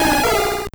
Cri de Capumain dans Pokémon Or et Argent.